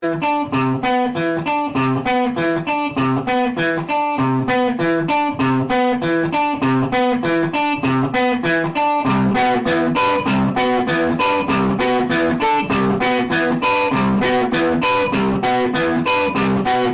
all roads leads to rome Meme Sound Effect